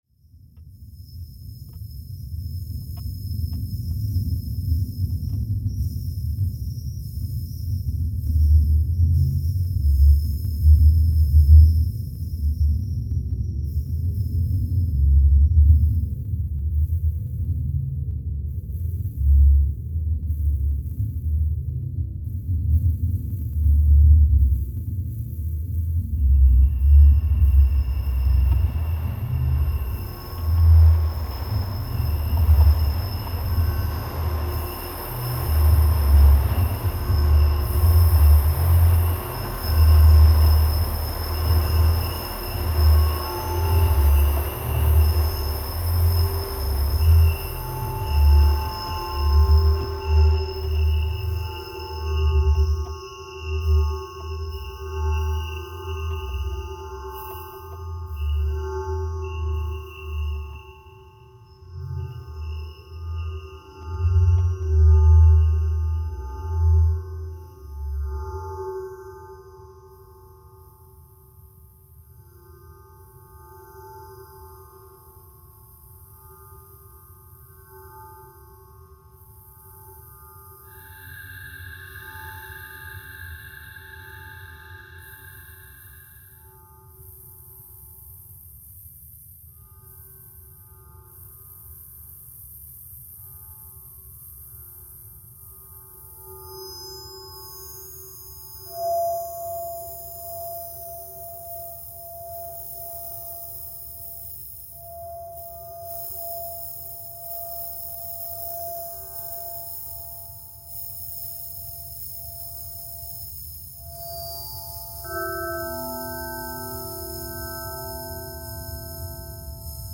stereo mixdown from dv and surround sound installation